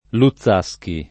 [ lu ZZ#S ki ]